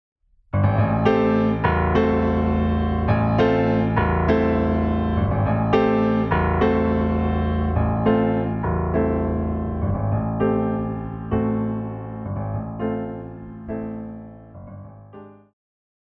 钢琴
演奏曲
世界音乐
仅伴奏
没有主奏
没有节拍器